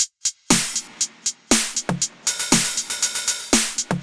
Index of /musicradar/retro-house-samples/Drum Loops
Beat 02 No Kick (120BPM).wav